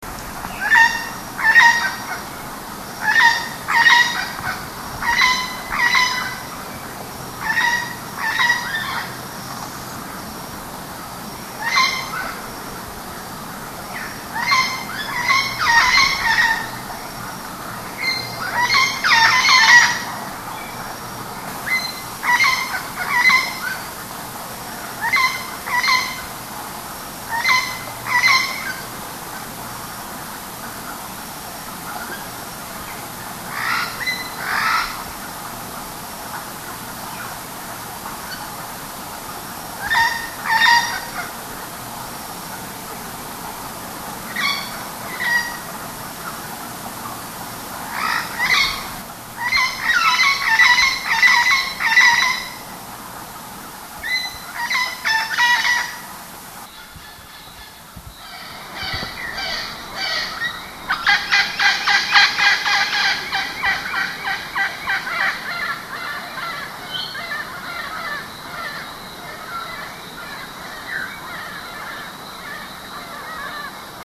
The Puerto Rican Parrot
Amazona vittata
puerto_rican_parrot.mp3